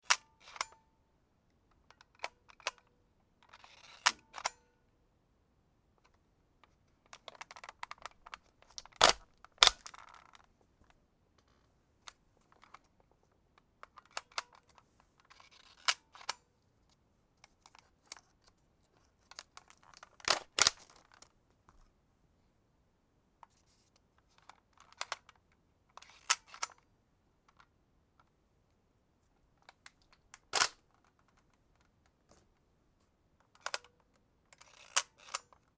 Instant Camera Polaroid i-zone